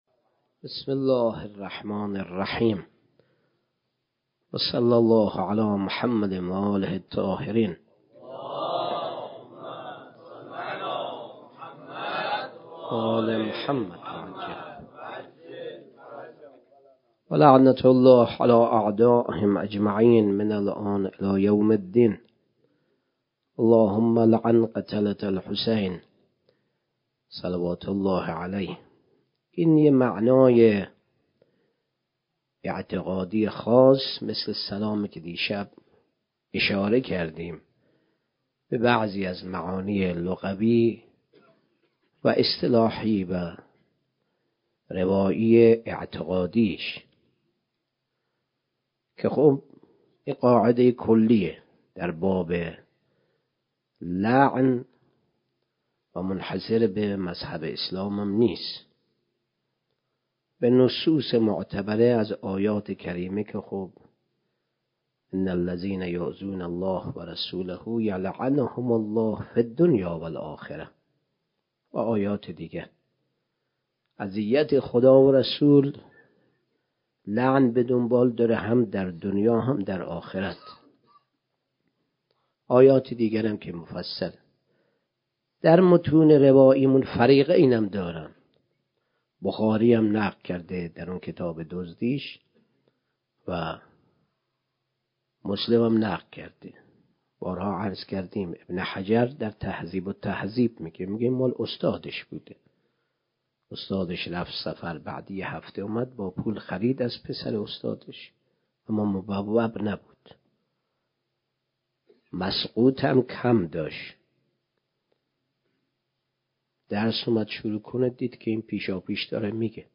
2 صفر 97 - غمخانه بی بی شهربانو - سخنرانی